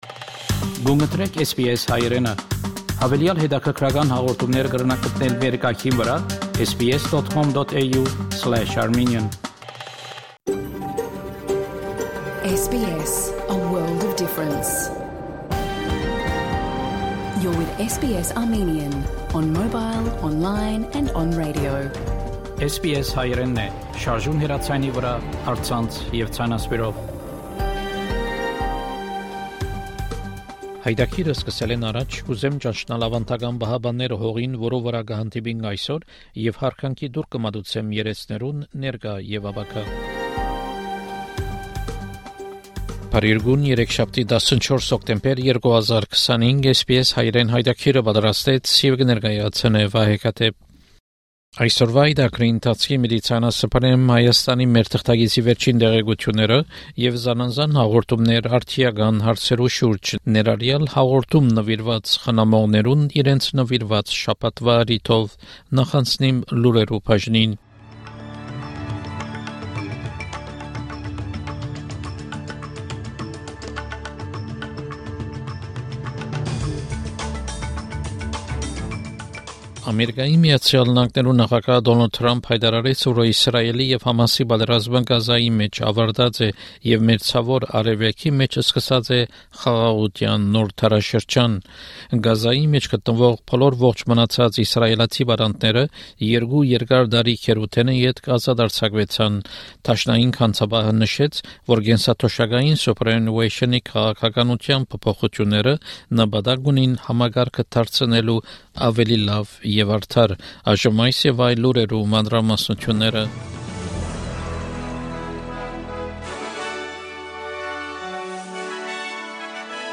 SBS Armenian news bulletin from 14 October 2025 program.